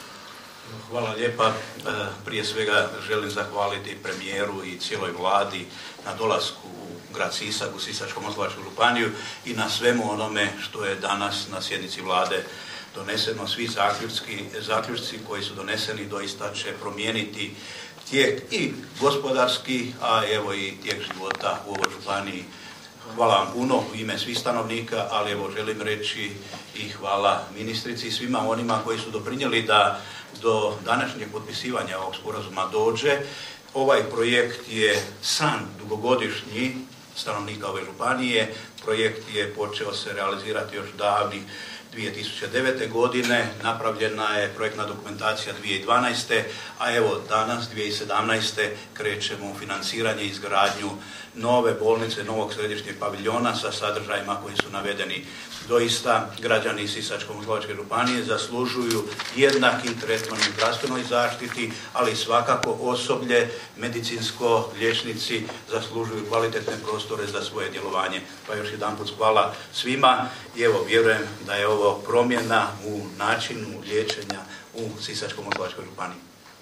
Sisačko-moslavački župan Ivo Žinić: